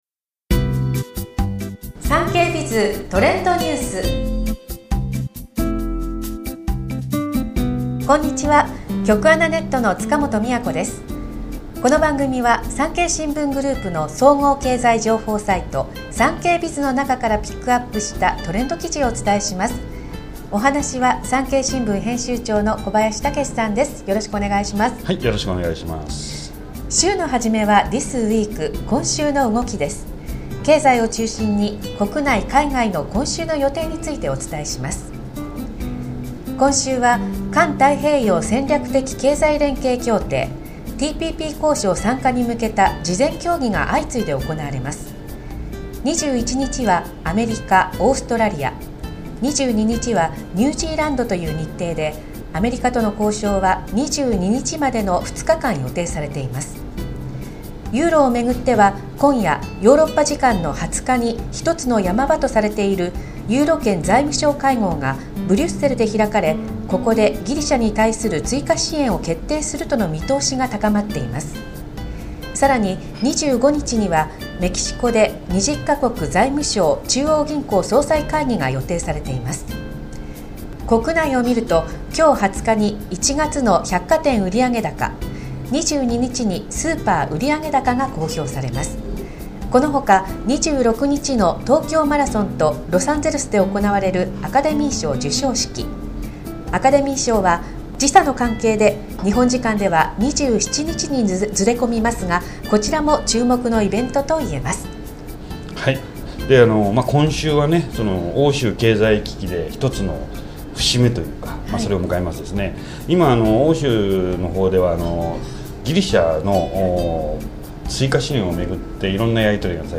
全国240名の登録がある局アナ経験者がお届けする番組「JKNTV」